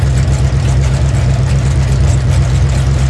rr3-assets/files/.depot/audio/Vehicles/v8_nascar/v8_idle_nascar2.wav
v8_idle_nascar2.wav